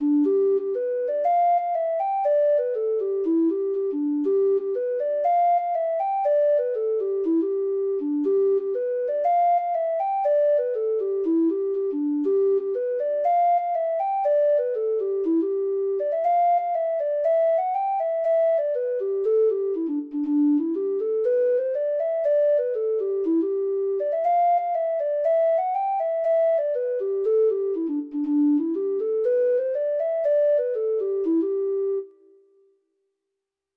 Traditional Trad. THE WINSOME WIDOW (Irish Folk Song) (Ireland) Treble Clef Instrument version
Traditional Music of unknown author.
Irish